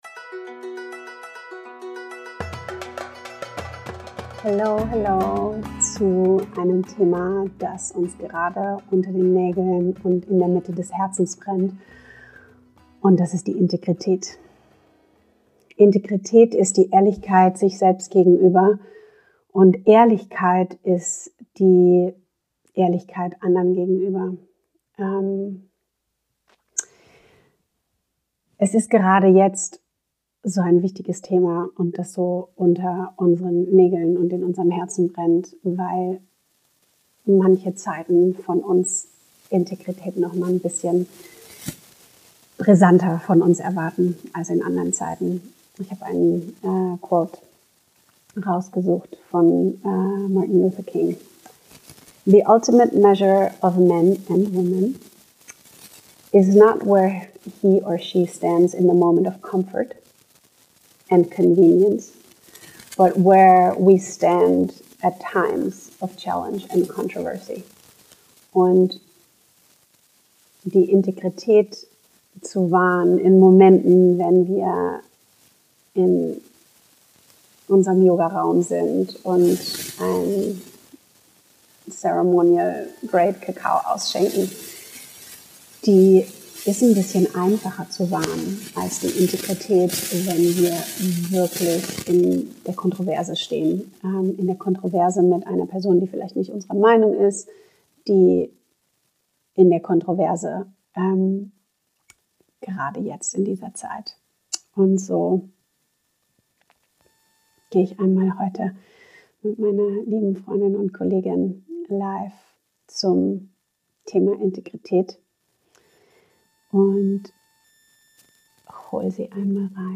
Spencer Johnson Ein Gespräch über Kongruenz: Wie wir zu unserer inneren Wahrheit kommen, welche Rolle der Körper und seine Impulse dabei spielen, wie hart es oft ist, Integrität zu leben.